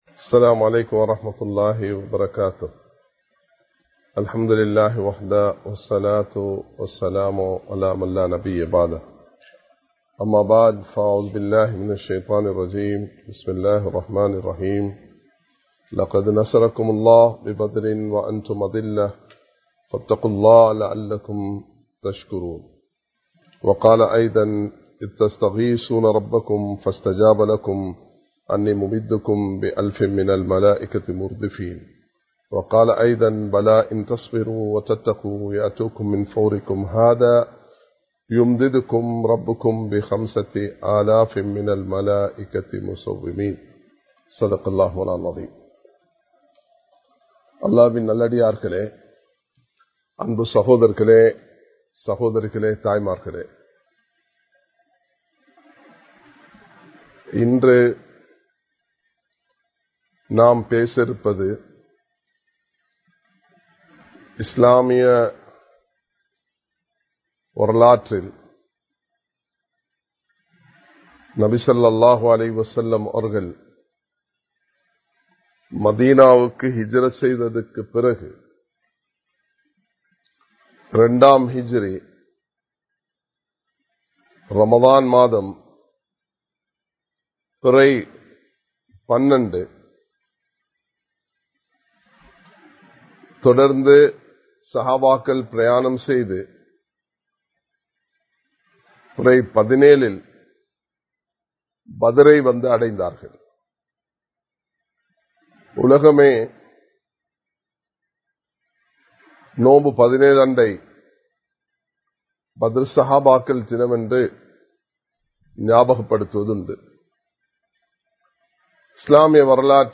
சரியாக புரிந்து கொள்வோம் | Audio Bayans | All Ceylon Muslim Youth Community | Addalaichenai
Live Stream